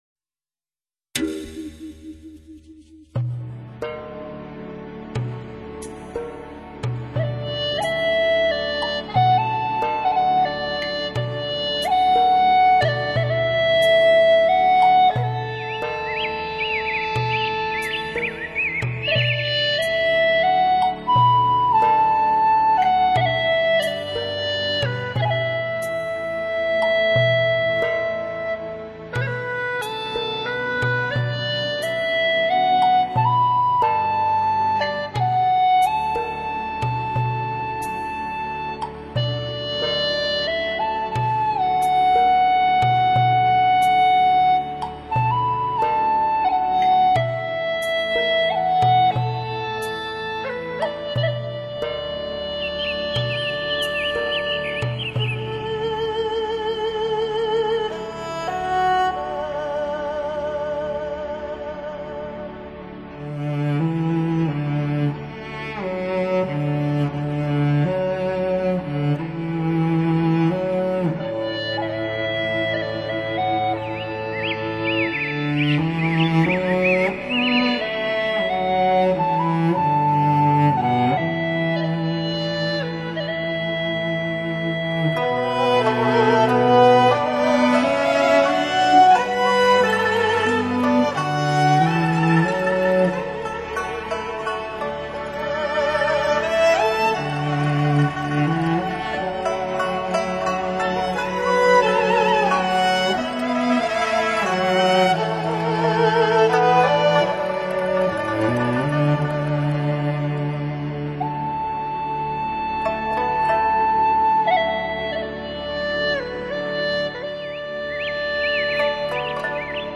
四重奏
特质的美感 无敌吸引力